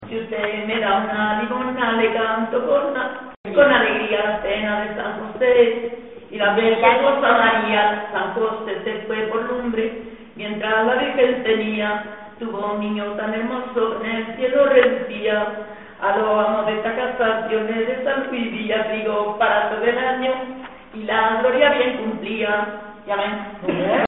Materia / geográfico / evento: Romances Icono con lupa
Zafarraya (Granada) Icono con lupa
Secciones - Biblioteca de Voces - Cultura oral